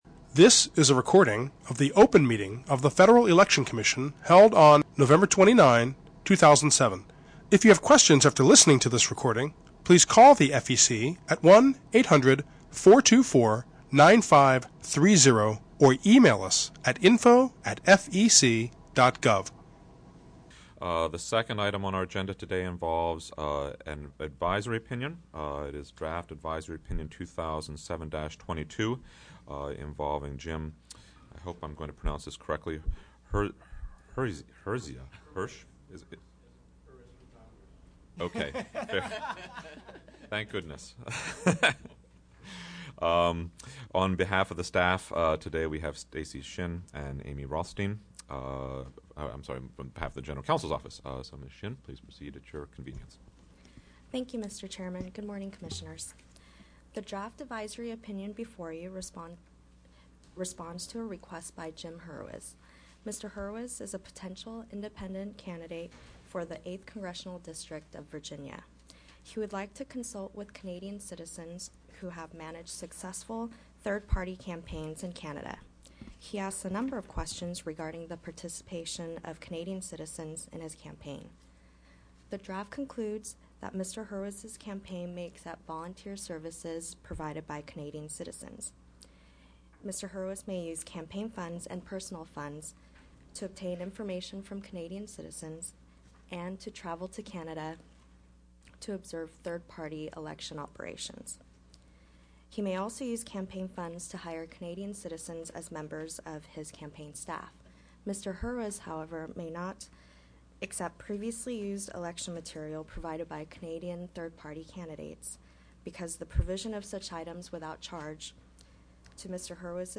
Open Meeting Agenda